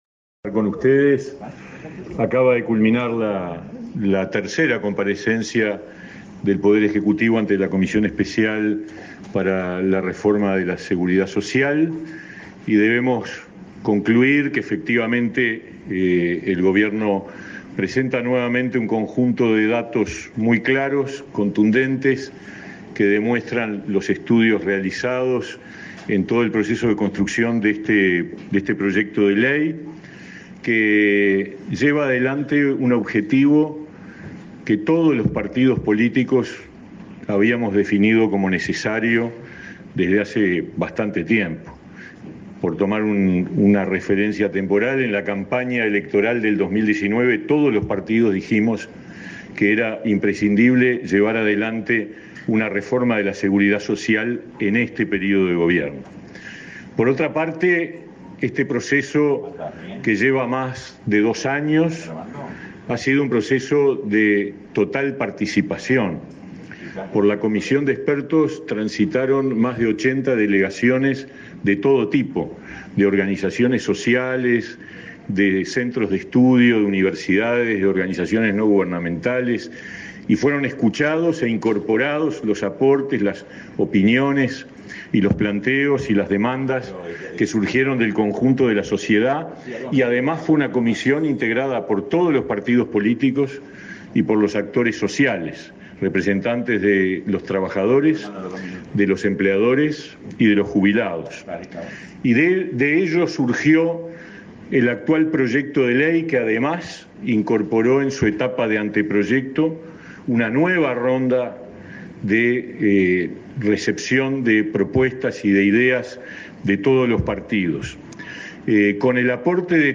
Conferencia de prensa de autoridades del Gobierno tras asistir a comisión del Senado que analiza la creación de un sistema previsional común
Tras el encuentro, las autoridades realizaron una conferencia de prensa.